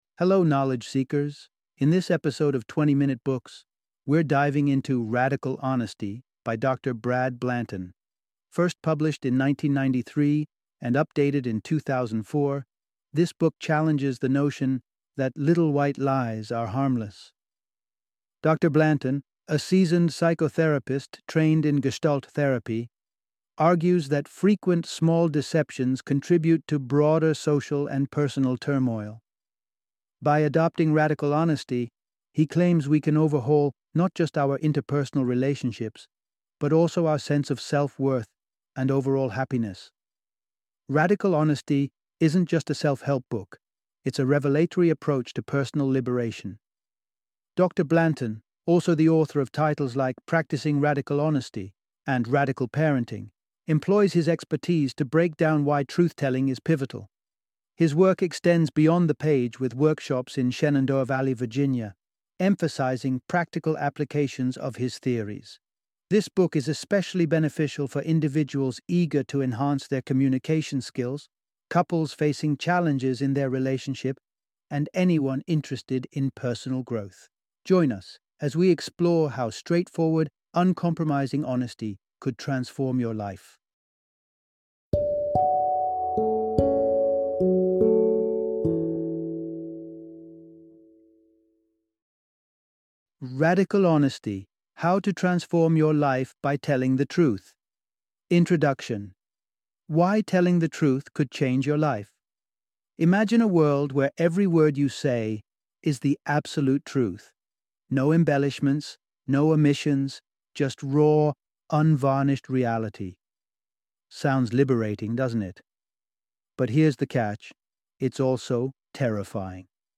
Radical Honesty - Audiobook Summary